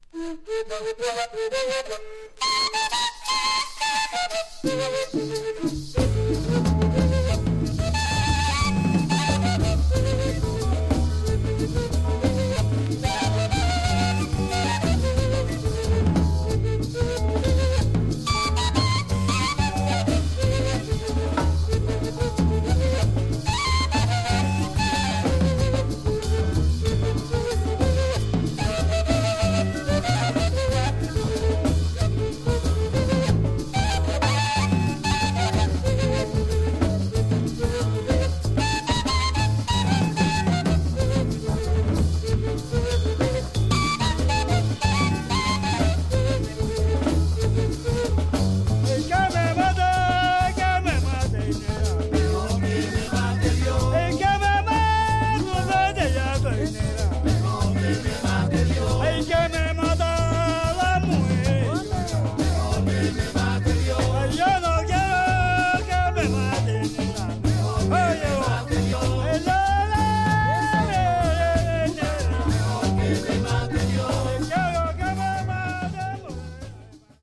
Tags: Folklorico , Tropical , Colombia , Bogotá
gaita con band